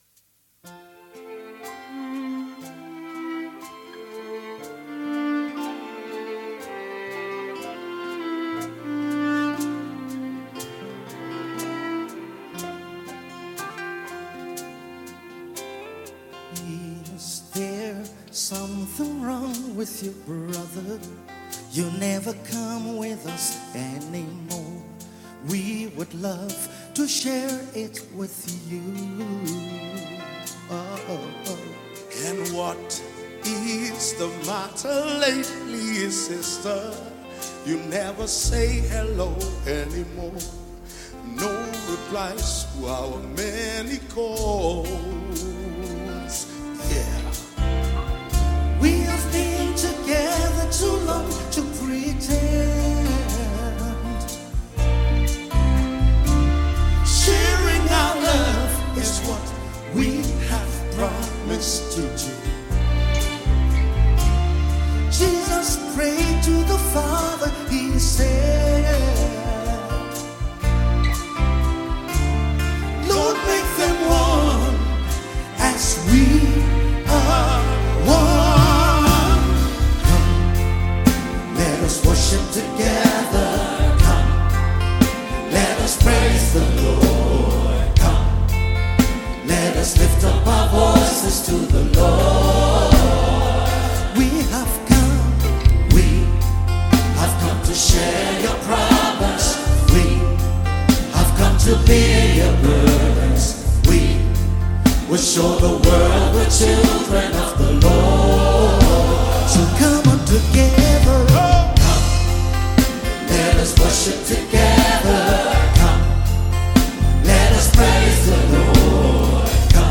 March 20, 2025 Publisher 01 Gospel 0